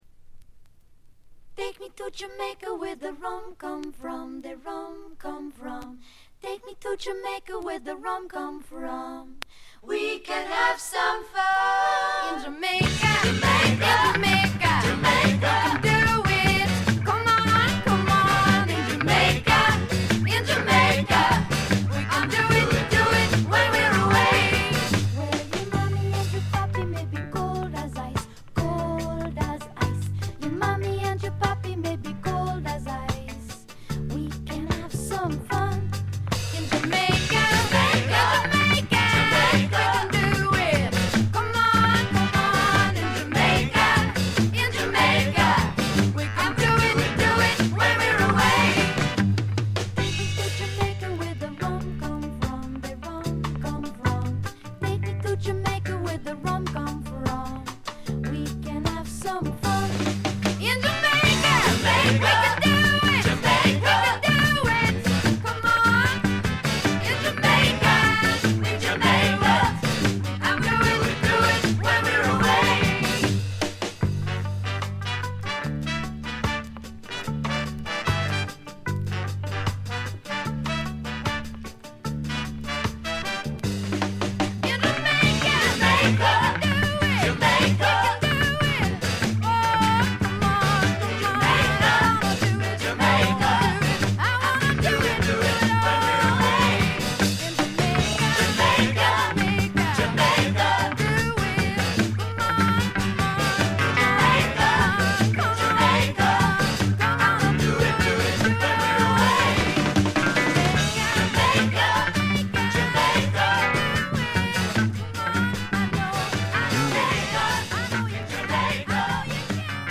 これ以外はところどころでチリプチ。
サウンドは時代なりにポップになりましたが可愛らしい歌声は相変わらずなのでおじさんはご安心ください。
クラブ人気も高い女性ポップの快作です。
試聴曲は現品からの取り込み音源です。